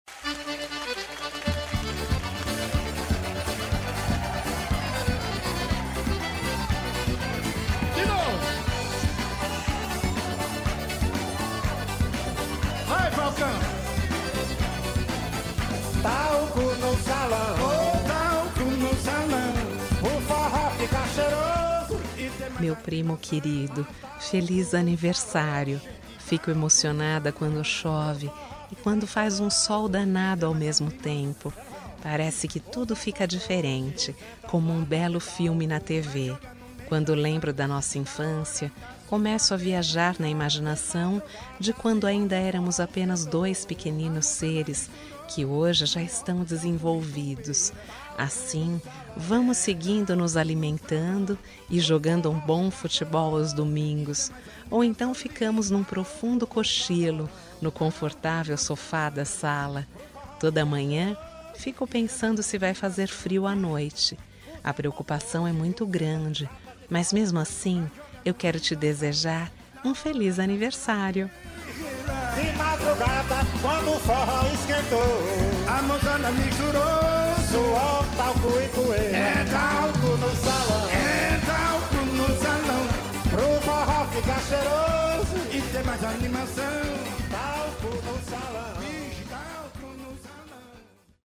Aniversário de Humor – Voz Feminina – Cód: 200112